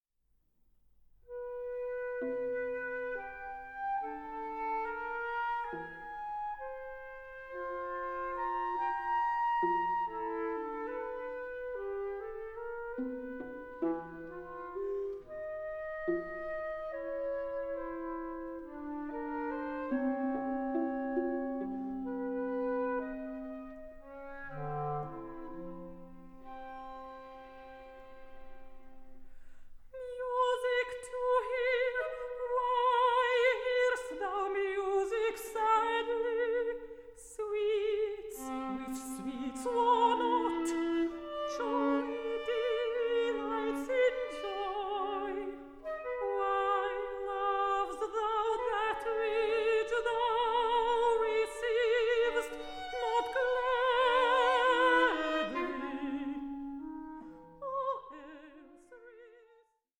AN EVENING OF INTIMATE SONGS AMONG FRIENDS
mezzo-soprano